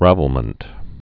(răvəl-mənt)